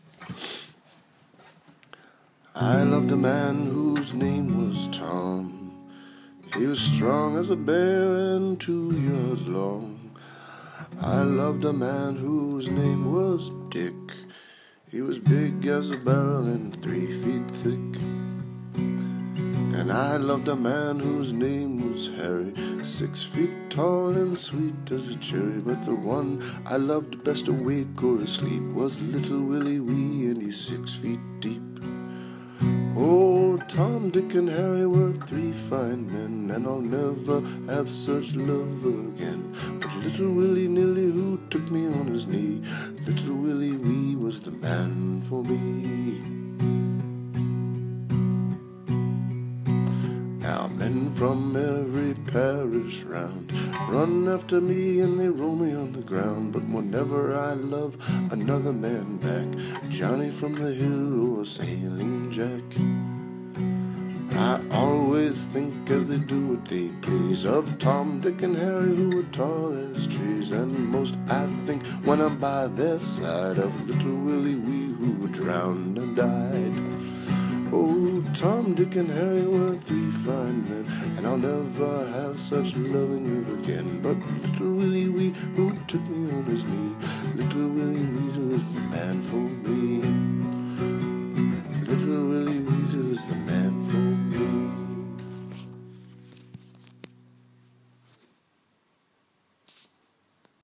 This is a bawdy bar room song of the type that have been around since the dawn of time.